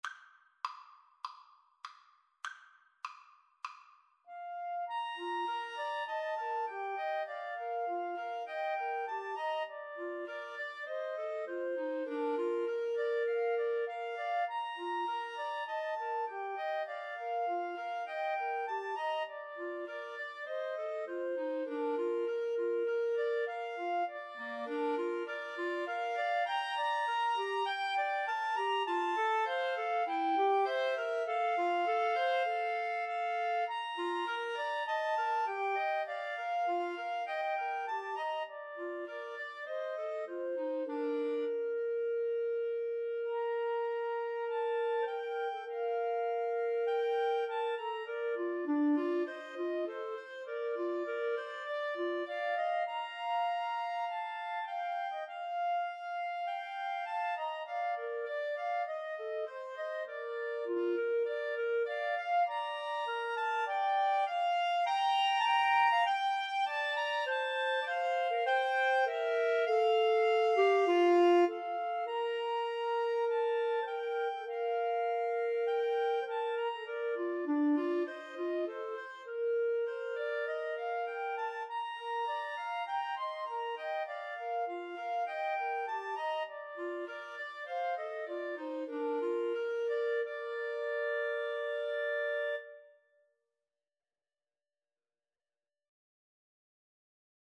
4/4 (View more 4/4 Music)
Andante